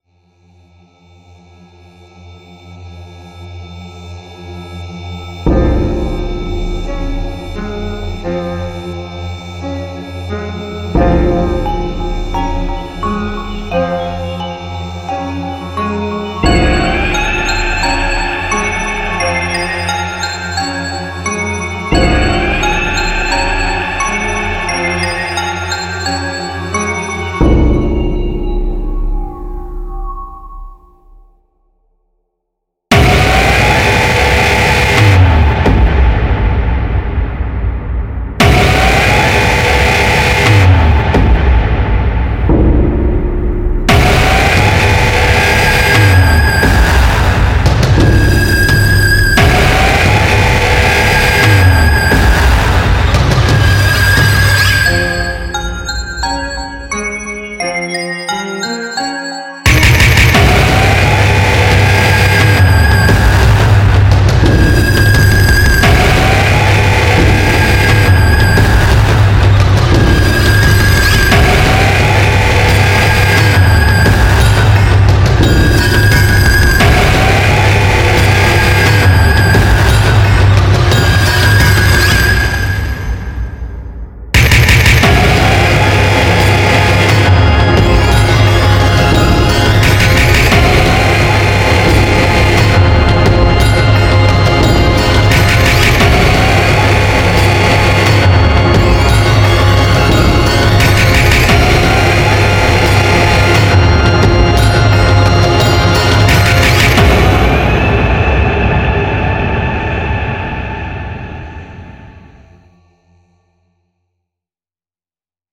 Музыка вселяющая страх